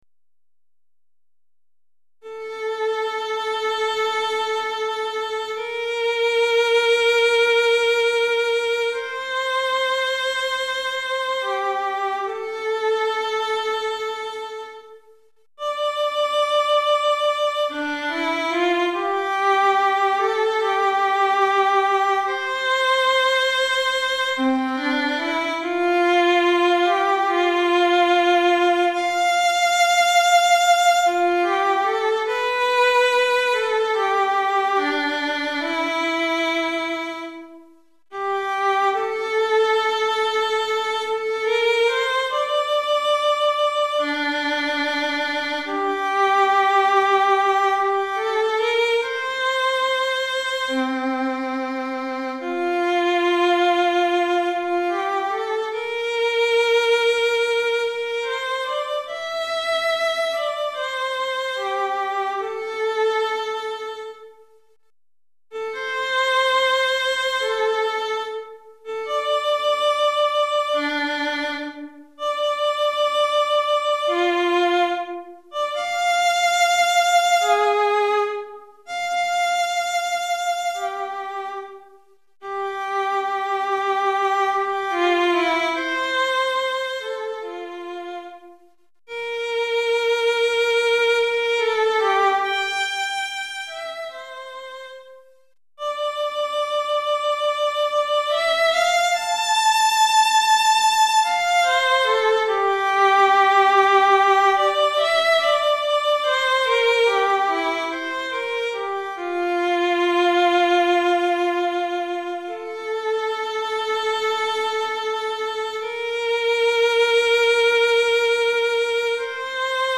Violon Solo